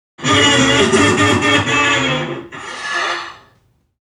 NPC_Creatures_Vocalisations_Robothead [42].wav